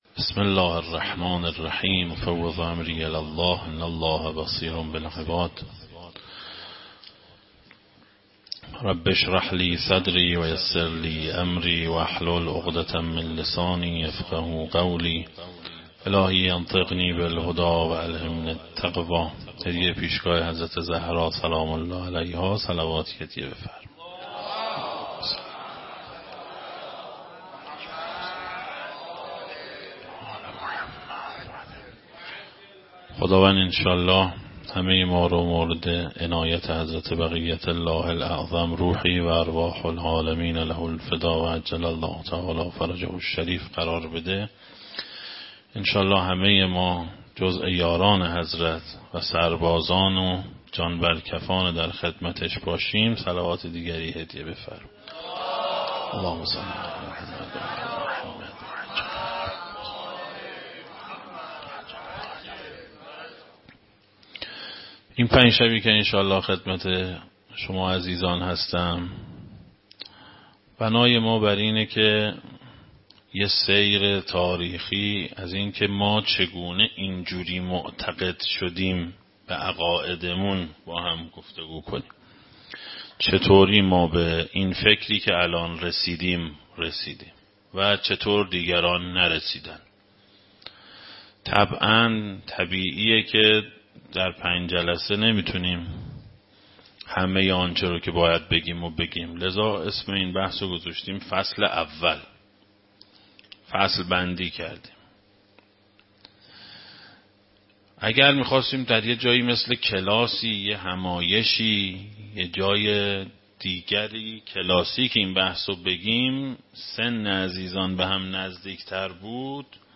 اولین شب از مراسم عزاداری ایام شهادت ام ابیها حضرت فاطمه زهرا سلام الله علیها در هیئت ثقلین تهران
سخنرانی